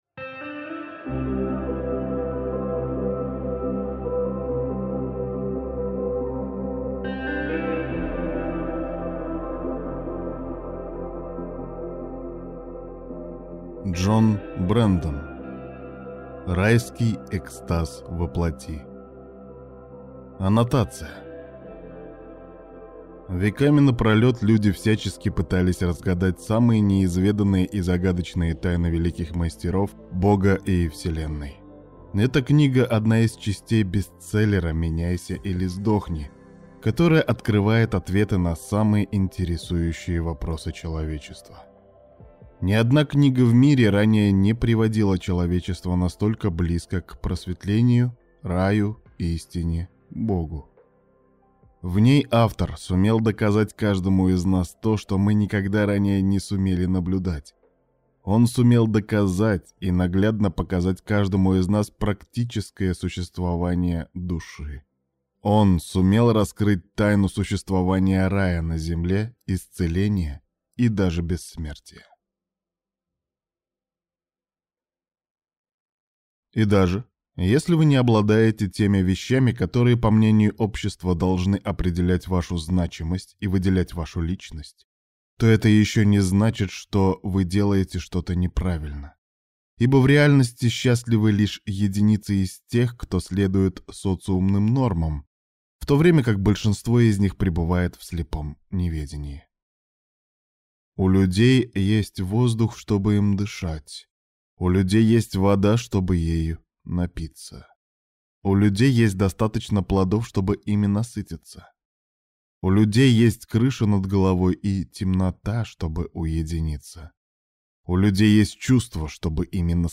Аудиокнига Райский экстаз во плоти | Библиотека аудиокниг